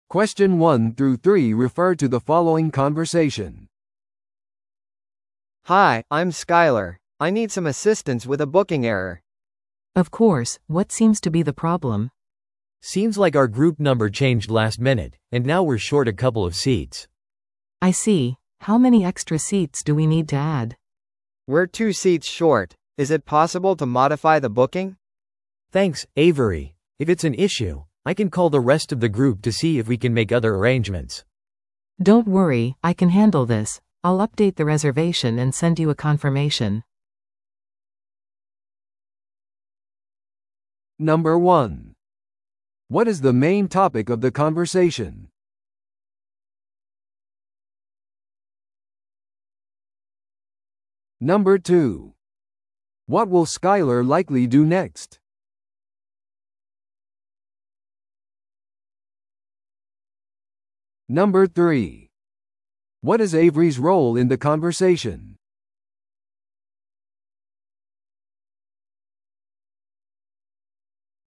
No.1. What is the main topic of the conversation?